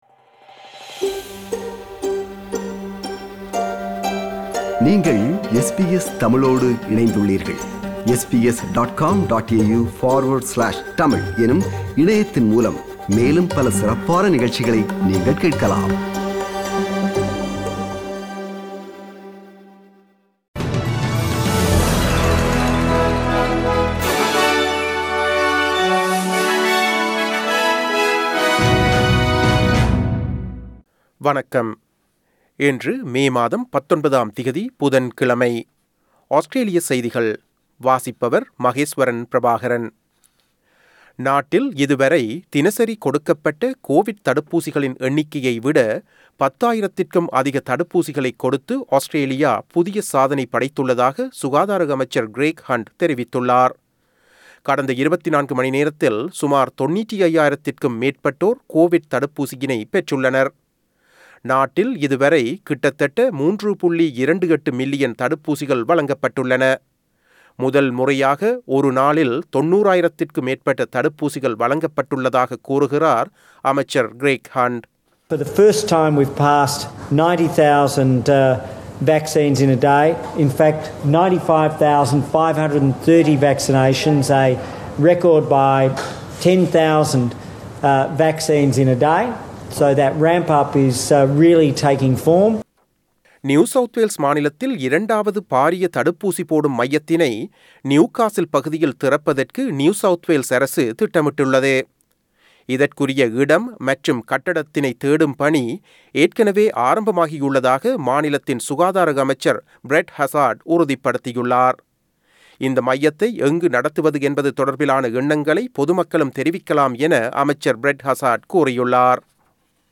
Australian news bulletin for Wednesday 19 May 2021.